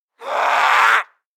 DayZ-Epoch/dayz_sfx/zombie/chase_3.ogg at cda602ece35d36fba564fc42e365b8f4717a1bdd
chase_3.ogg